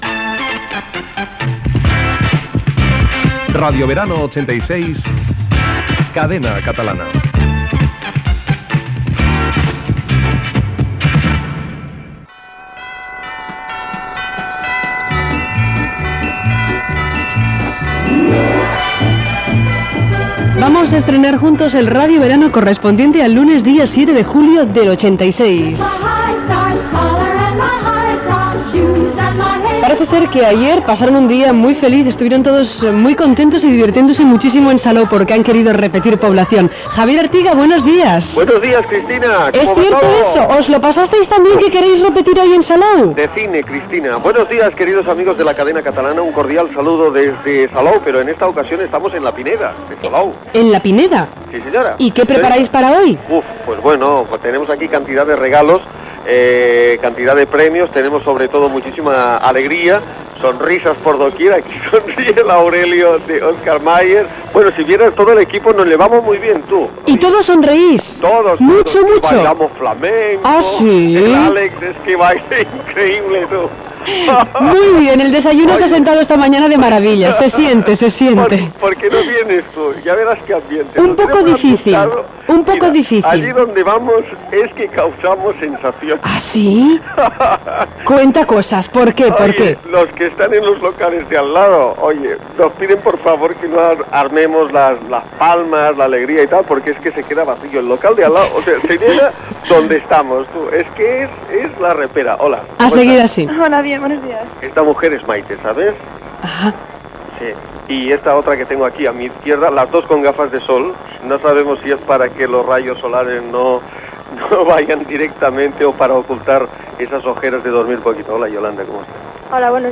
Identificació del programa, connexió amb la platja de la Pineda de Salou, publicitat i indicatiu de la cadena.
Entreteniment
FM